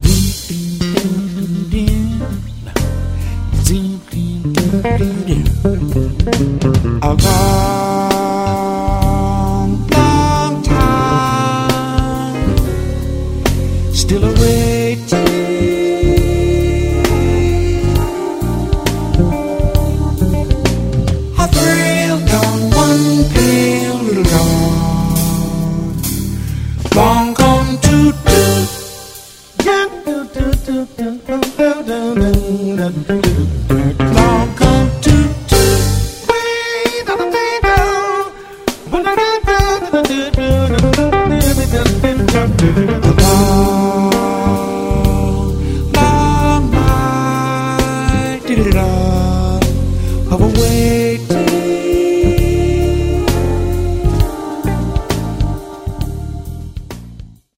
gitarą